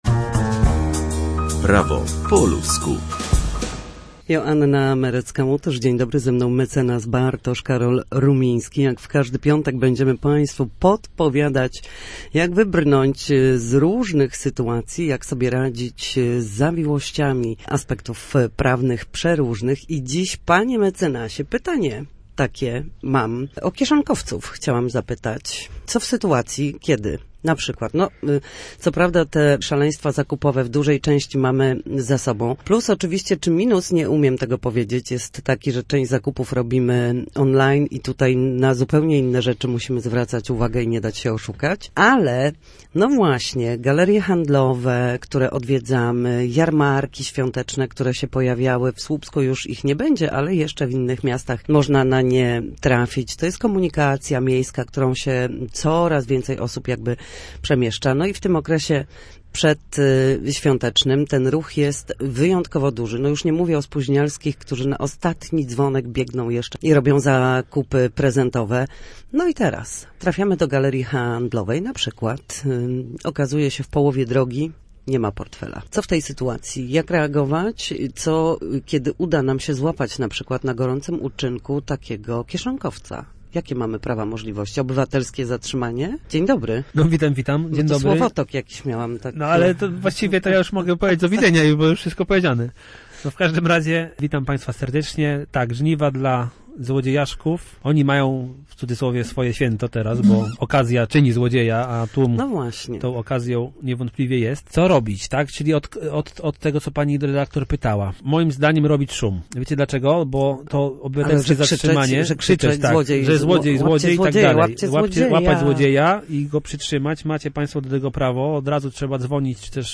W każdy piątek o godzinie 7:20 i 13:40 na antenie Studia Słupsk przybliżamy państwu meandry prawa. Nasi goście, prawnicy, odpowiadać będą na jedno pytanie dotyczące zachowania w sądzie czy podstawowych zagadnień prawniczych.